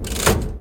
lever3.ogg